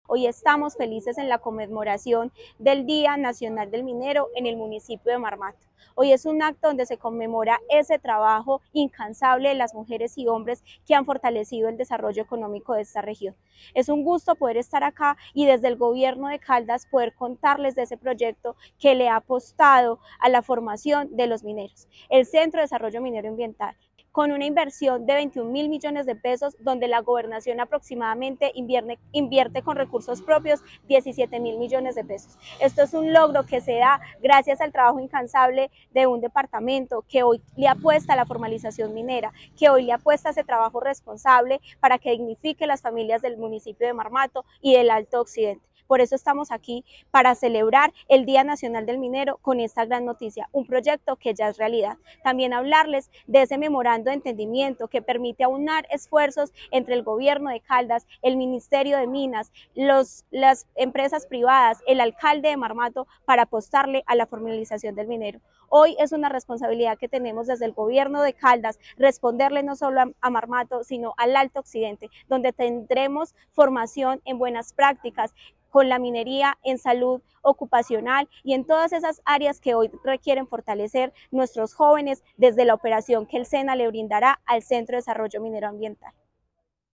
Tania Echeverry – Secretaria de Desarrollo, Empleo e Innovación de Caldas.